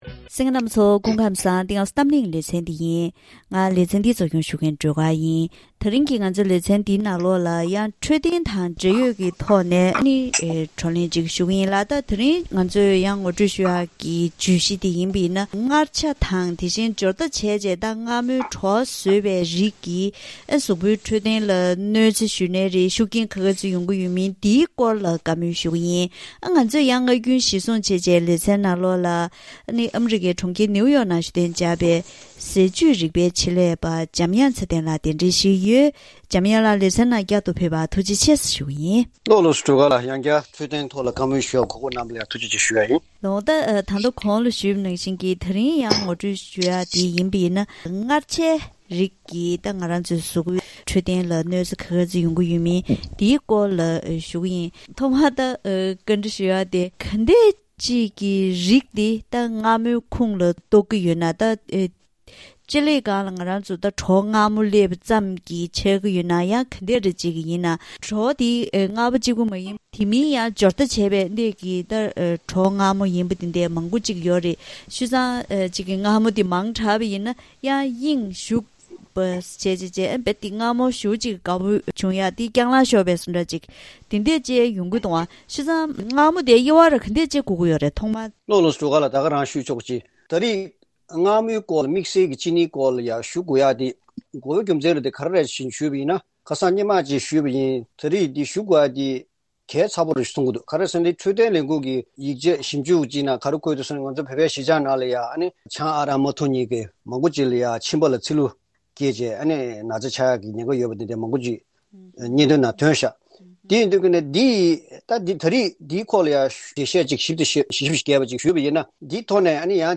ད་རིང་གི་གཏམ་གླེང་ཞལ་པར་ལེ་ཚན་ནང་ མངར་ཆ་ནི་འཚོ་བའི་ནང་མི་མང་ཆེ་བས་དགའ་པོ་བྱེད་པའི་ཟས་རིགས་ཤིག་ཆགས་ཡོད་ལ། ཉིན་ལྟར་ཟས་རིགས་མང་པོའི་ནང་མངར་ཆ་བེད་སྤྱོད་བྱེད་སྲོལ་ཡོད་པ་དང་། ལྷག་པར་དུ་དེང་དུས་ཀྱི་འཚོ་བའི་ཁྲོད་མངར་ཆས་བཟོས་པའི་ཟས་རིགས་ཧ་ཅང་མང་པོ་ཐོན་དང་ཐོན་བཞིན་ཡོད་པས། མངར་ཆ་དང་སྦྱོར་བརྡ་བྱས་ཏེ་མངར་མོའི་བྲོ་བ་བཟོས་པའི་རིགས་ཀྱིས་གཟུགས་པོའི་འཕྲོད་བསྟེན་ལ་གནོད་འཚེ་ཇི་ཙམ་ཡོད་མེད་སྐོར་ལ་བཀའ་མོལ་ཞུས་པ་ཞིག་གསན་རོགས་གནང་།